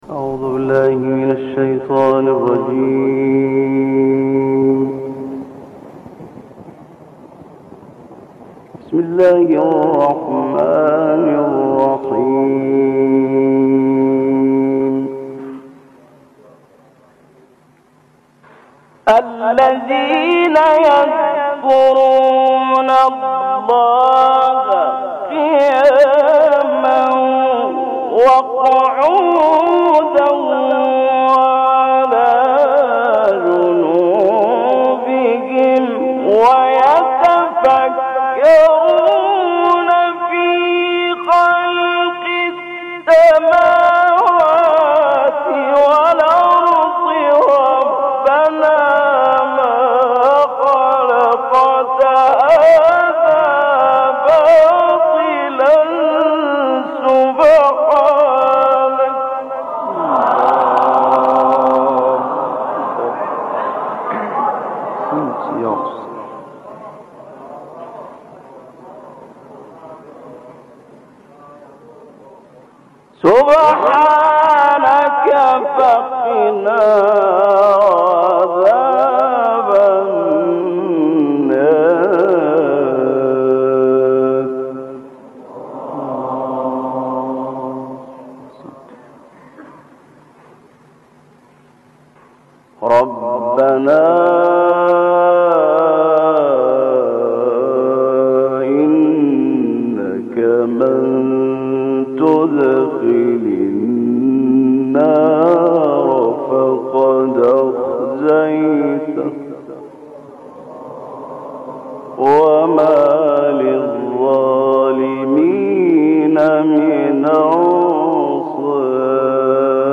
فایل صوتی تلاوت آیات191 تا 195 از سوره مبارکه آل عمران که 8 دقیقه اش تقطیع شده را با هم می‌شنویم.
و در پایان هم حجاز نوا اجرا شده و قاری از این دو مقام در تلاوت خودش در مقطعی که ما می‌شنویم استفاده کرده و هدفش هم این نبوده که خیلی تنوع ردیف و تنوع مقام ایجاد کند برای این‌که آن حس و حال مفاهیم و در واقع مناجات را می‌خواسته کماکان حفظ کند تا پایان قطعه.